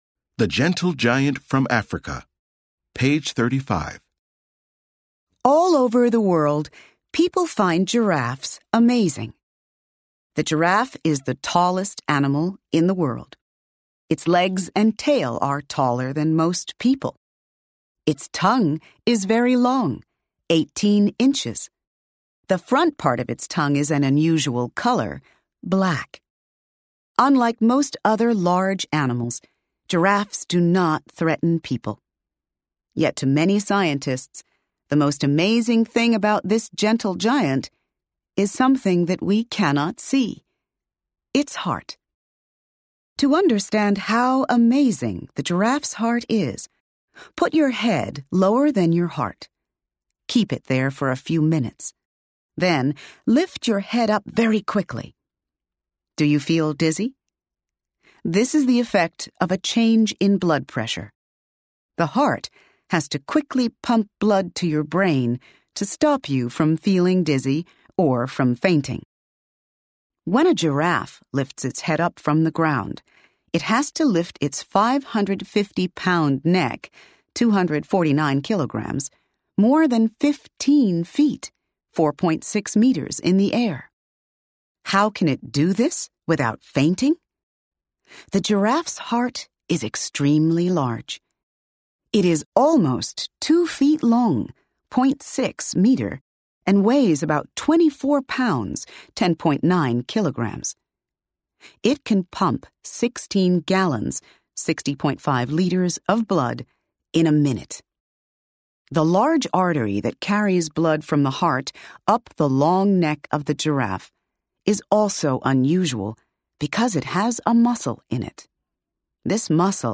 Bound into the back of the book is an audio CD that contains audio recordings of all the stories in the Student's Book.